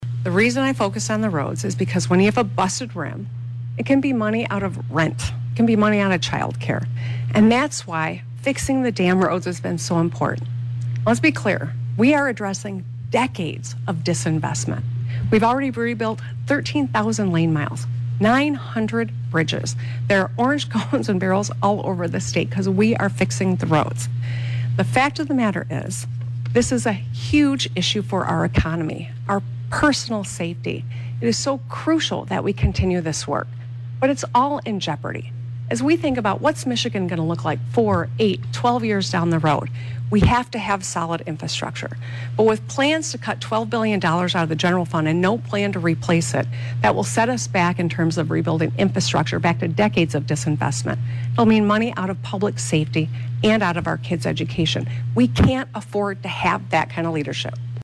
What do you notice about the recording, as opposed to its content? The debate brought the two contenders together for hour-long event at Oakland University.